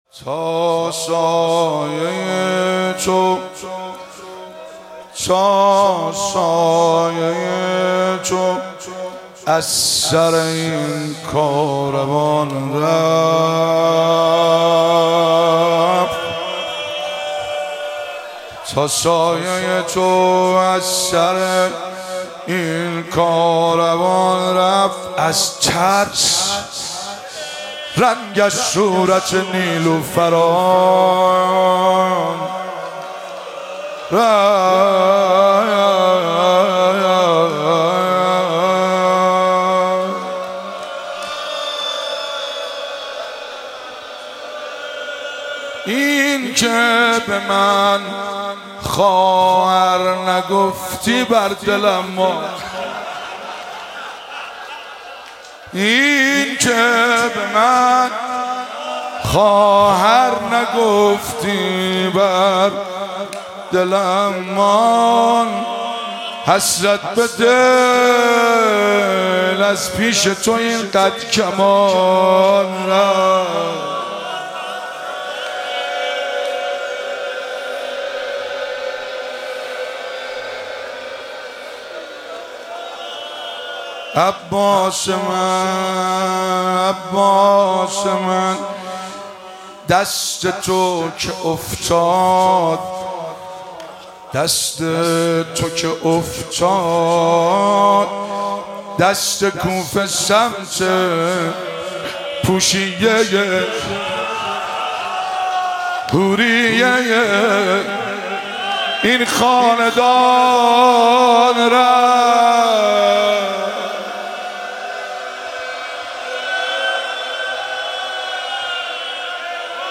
مراسم عزاداری شب تاسوعا محرم 1445
روضه- تا سایه‌ی تو از سر این کاروان رفت